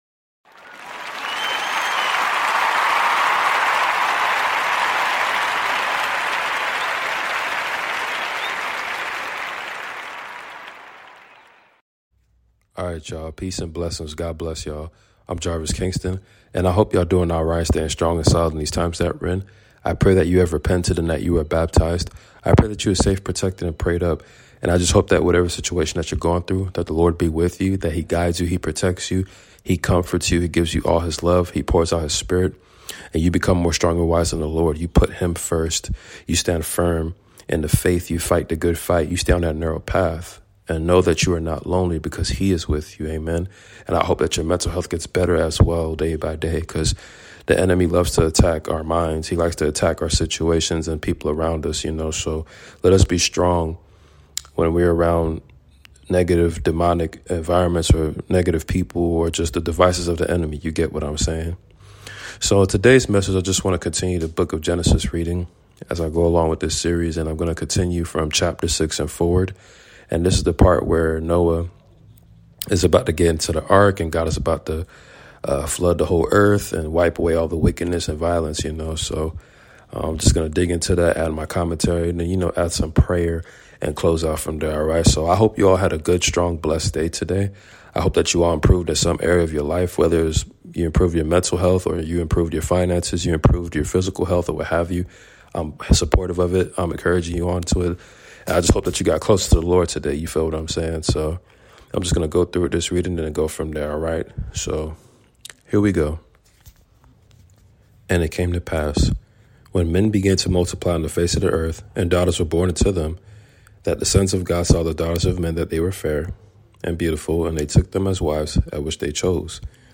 Genesis reading and prayers !